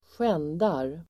Ladda ner uttalet
Uttal: [²sj'en:dar]